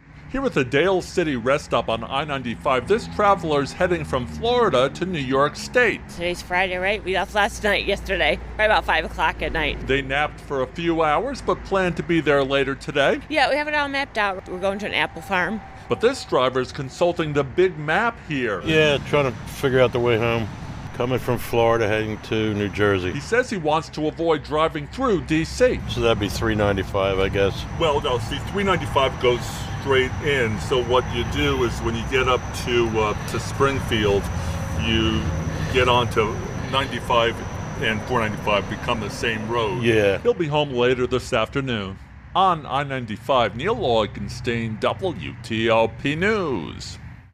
talks to travelers who are trying to beat potential heavy traffic ahead of long Labor Day weekend